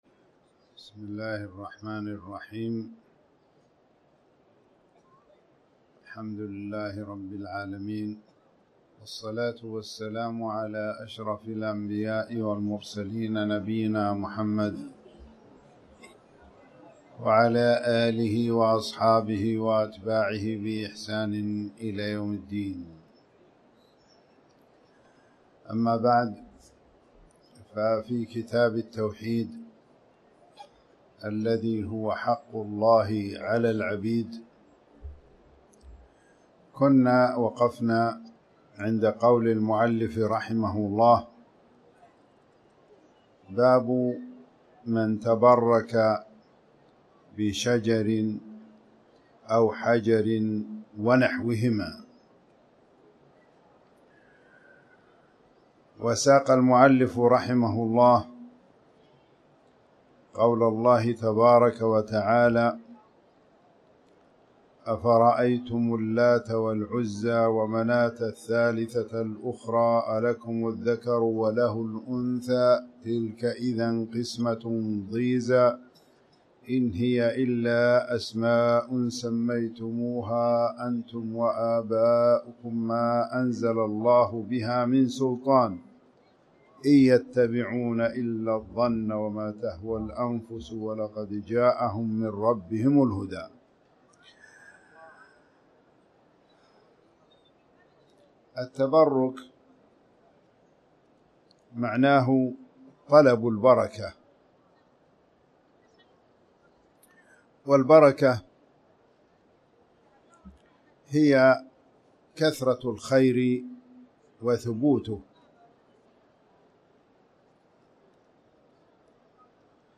تاريخ النشر ٨ رجب ١٤٣٩ هـ المكان: المسجد الحرام الشيخ